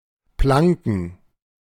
Planken (German pronunciation: [ˈplaŋkn̩]